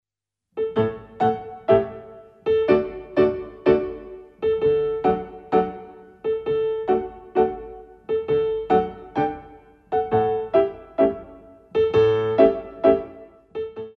32. Polka